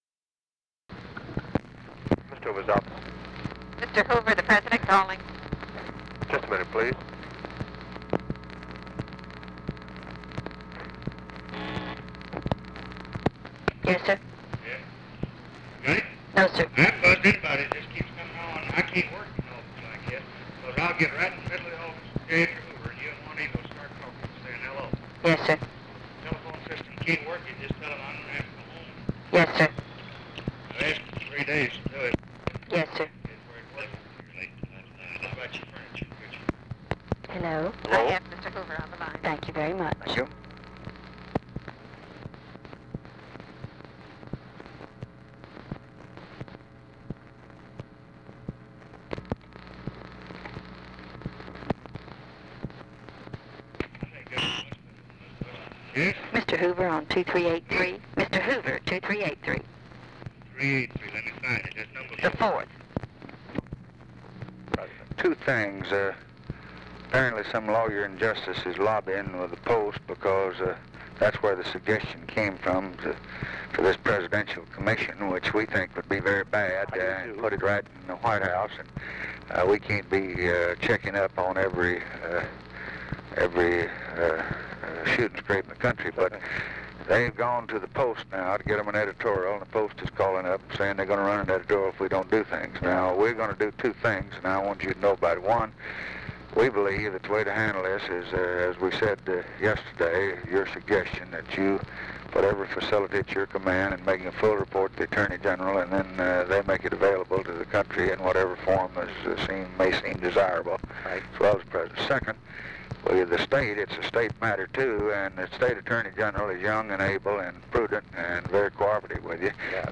Conversation with J. EDGAR HOOVER, November 25, 1963
Secret White House Tapes